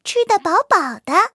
卡尔臼炮补给语音.OGG